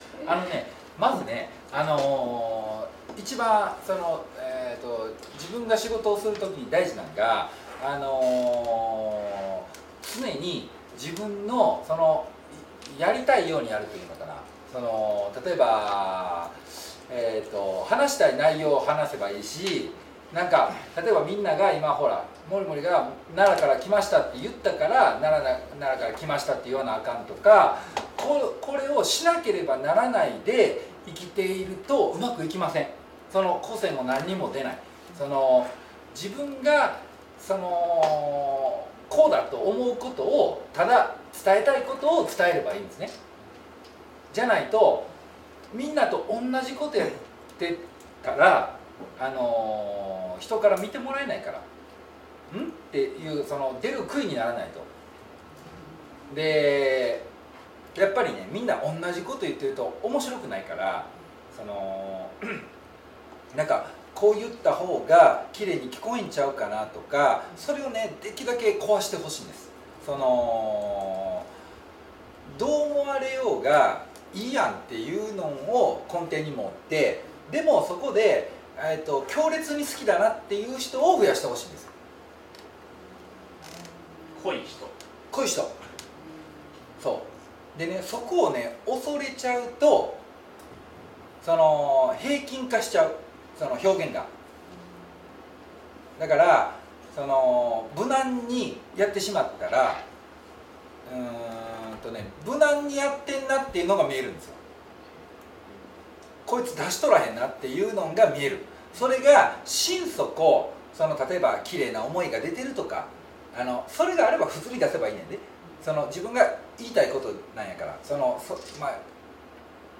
セミナー１日目の冒頭です。